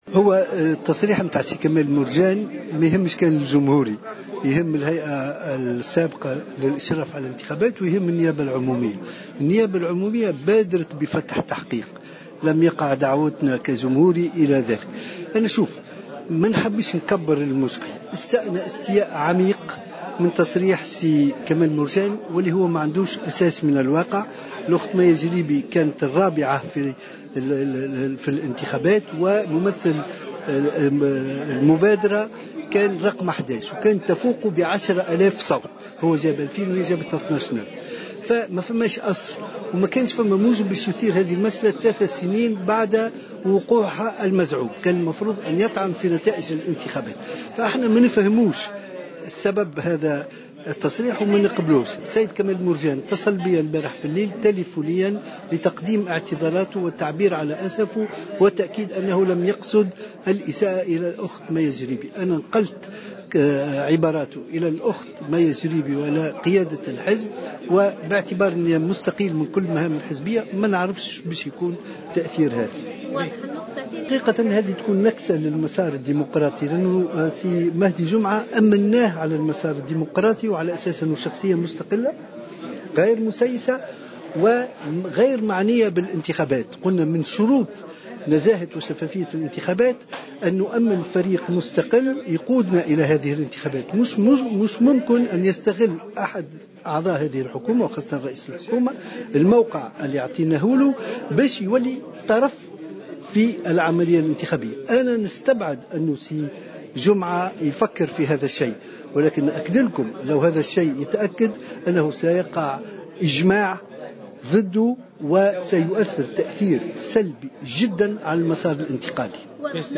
قال أحمد نجيب الشابي المرشح للانتخابات الرئاسية المقبلة في تصريح لجوهرة أف أم...